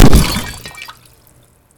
sfx_large_cell_blown.wav